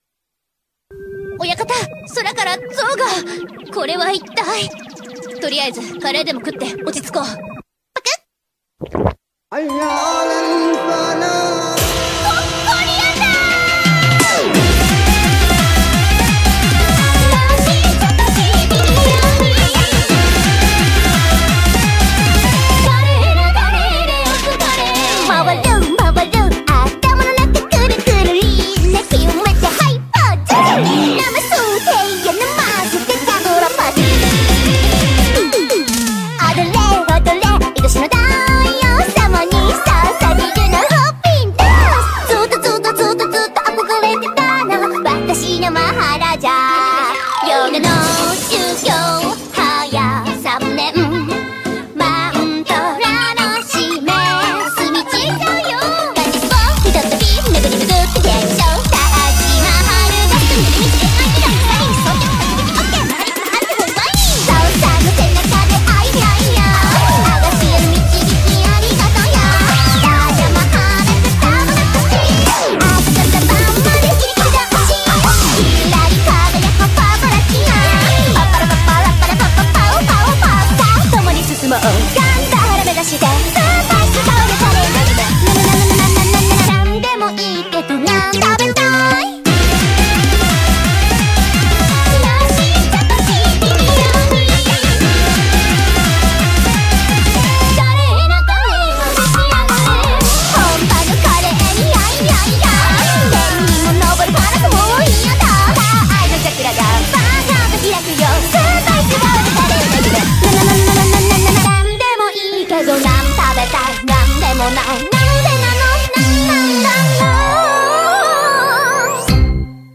BPM87-173
Audio QualityPerfect (Low Quality)